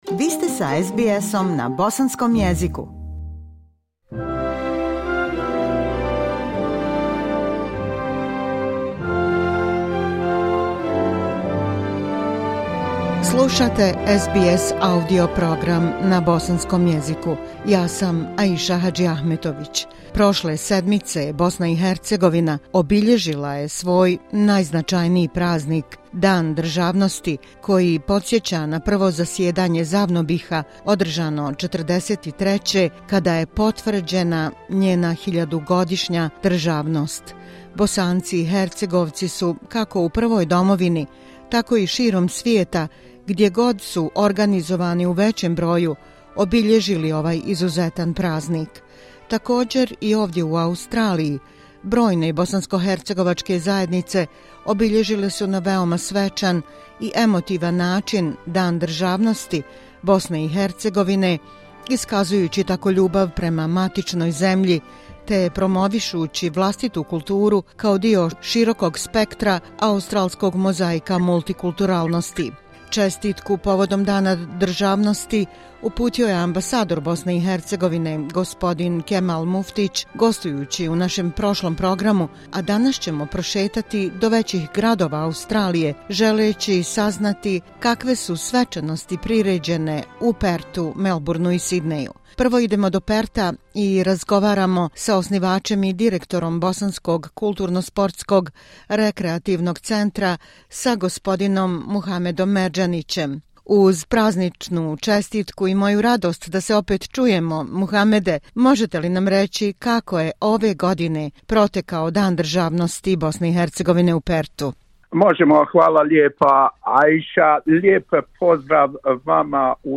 Brojne bosanskohercegovačke zajednice širom Australije obilježile su Dan državnosti BiH na veoma svečan i emotivan način, iskazujući ljubav prema matičnoj zemlji, te promovišući vlastitu kulturu kao dio širokog spektra mozaika multikulturalne Australije. Dajući doprinos obilježavanju ovog važnog praznika prošetali smo se većim gradovima Australije, i zavirili na svečanosti koje su priređene u Melbourneu, Perthu i Sydneyu.